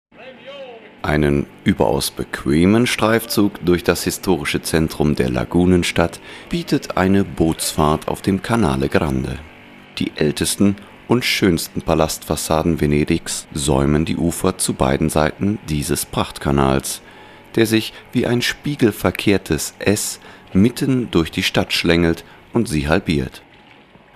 Stimmalter: 30-45 Stimmfarbe: weich, seriös, musikalisch, Sonor mit großer Präsenz Genre: Feature, Moderation, Nachrichten, Sachtext, Off-Text Dokumentationen, Telefonansagen, Werbung
Deutscher Sprecher, Musiker.
Sprechprobe: Sonstiges (Muttersprache):